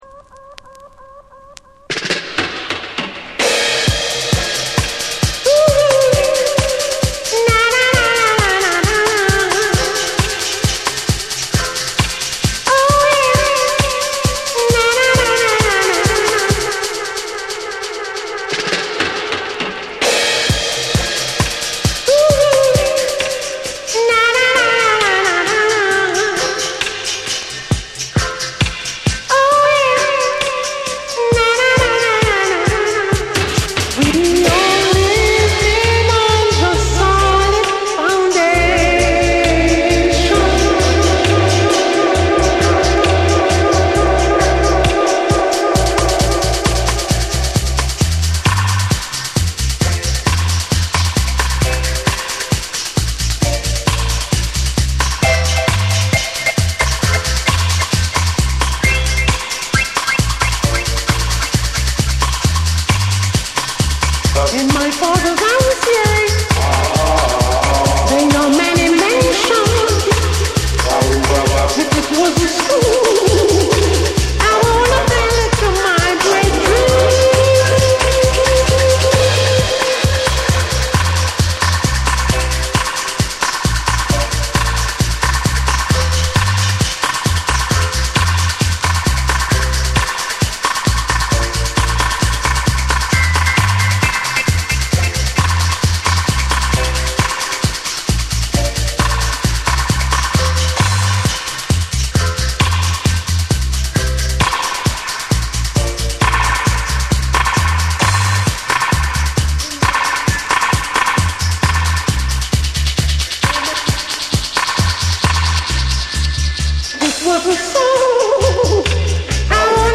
名門ルーツ・ヴォーカル・グループ
重厚なベースと空間処理が光る、ルーツ〜ダブ好きに外せない1枚。